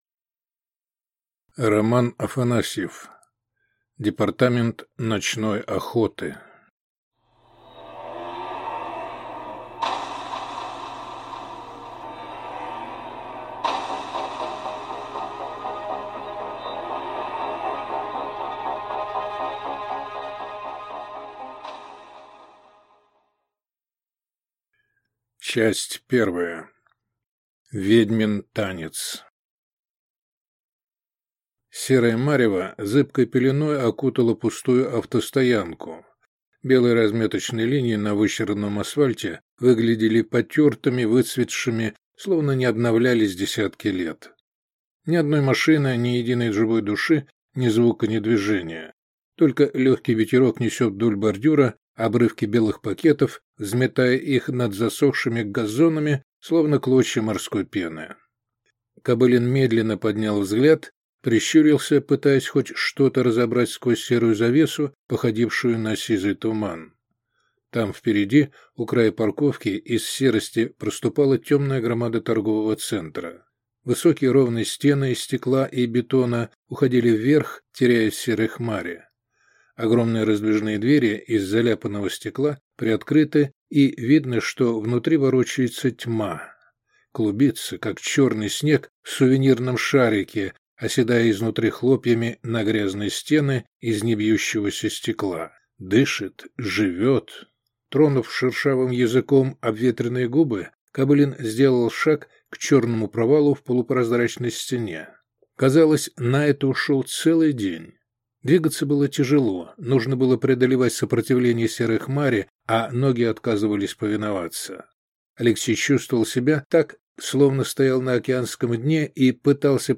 Аудиокнига Департамент ночной охоты | Библиотека аудиокниг